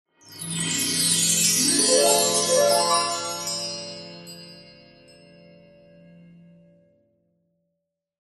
Звук, в котором волшебная золотая рыбка исполняет желание